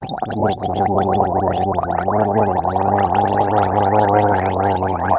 Горло полощут, промывают
Тут вы можете прослушать онлайн и скачать бесплатно аудио запись из категории «Анатомия, тело человека».